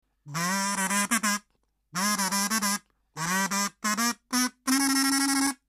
木のカズ―｜手づくり楽器 ～ 音 遊 具 ～